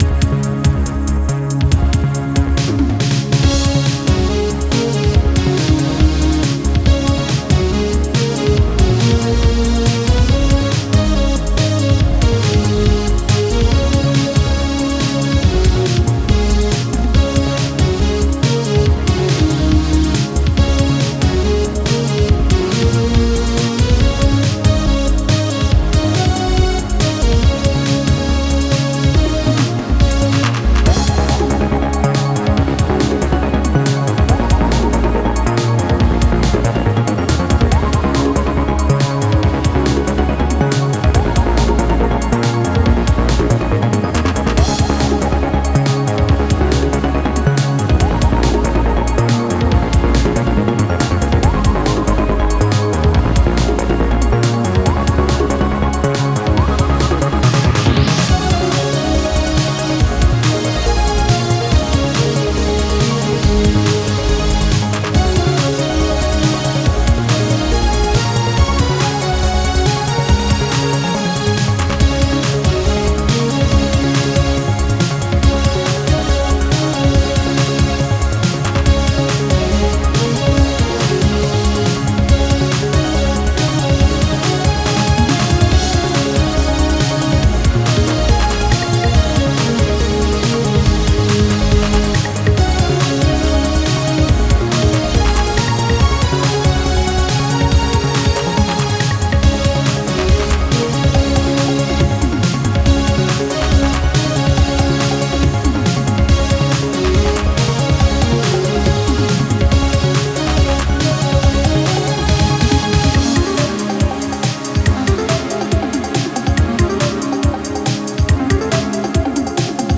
Synthwave music for field theme.